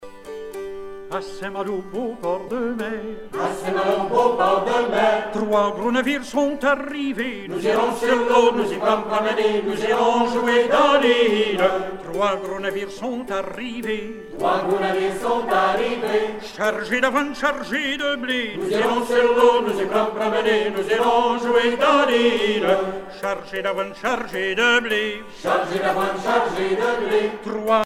Genre laisse
Catégorie Pièce musicale éditée